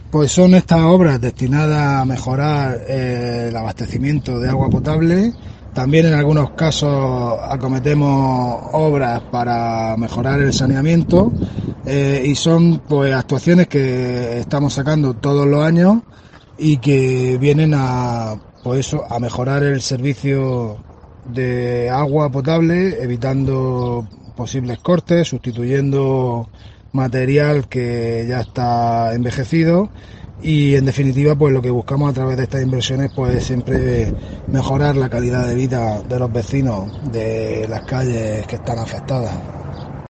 César Gázquez, concejal Ayto Puerto Lumbreras